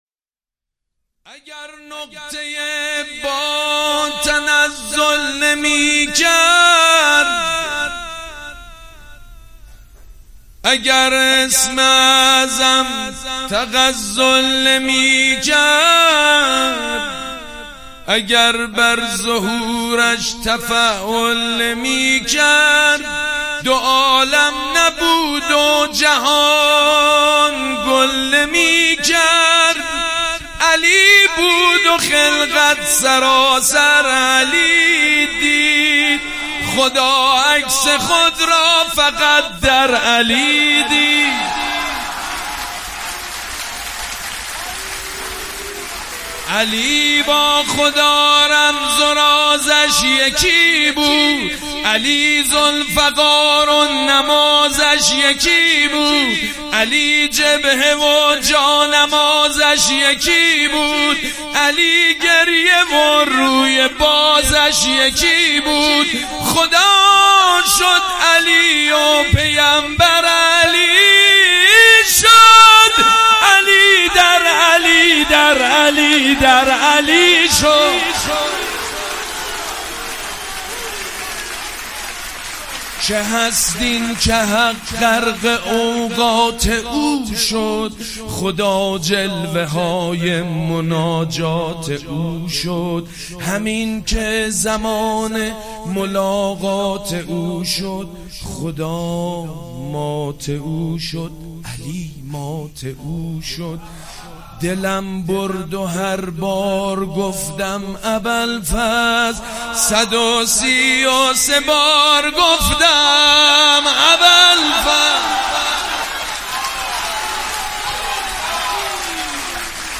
مولودی جدید حاج سید مجید بنی فاطمه شب میلاد حضرت ابوالفضل العباس (ع) سه شنبه 20 فروردین 1398 هیات مکتب الزهرا (س) تهران